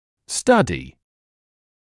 [‘stʌdɪ] [‘стади] исследование; изучать, исследовать